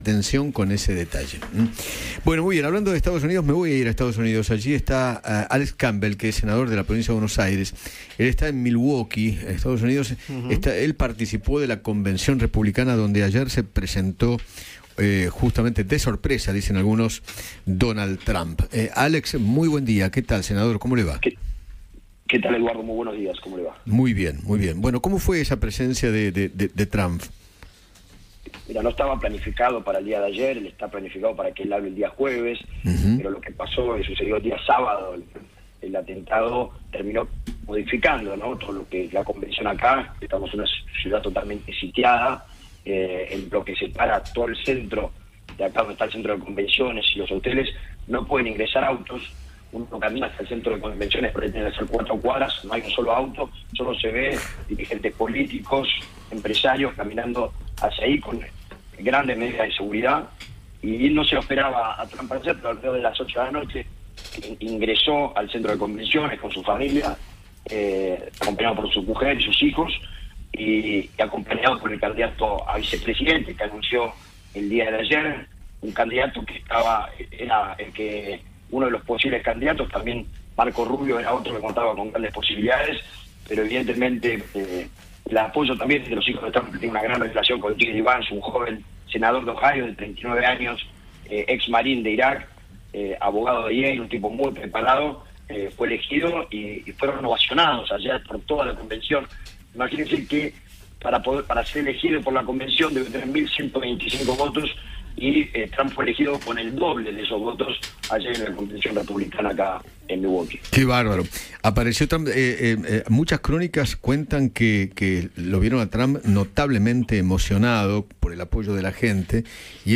Eduardo Feinmann habló con el senador bonaerense Alex Campbell, quien estuvo en Estados Unidos participando de la Convención Nacional Republicana, donde reapareció Donald Trump tras el atentado en su contra.